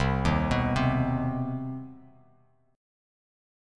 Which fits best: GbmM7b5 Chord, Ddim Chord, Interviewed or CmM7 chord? CmM7 chord